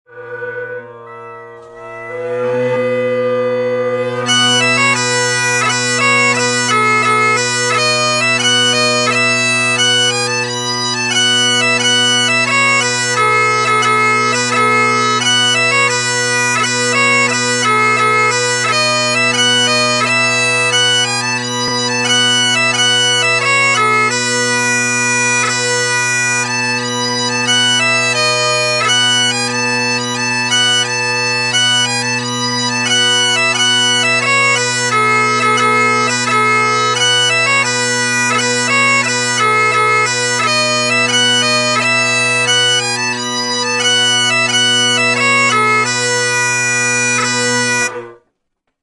Дуют в волынку и играют мелодии